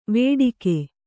♪ vēḍike